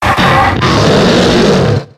Audio / SE / Cries / HYDREIGON.ogg